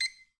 Звуки беговой дорожки
Звук писка беговой дорожки при нажатии кнопки старта или выбора режима бип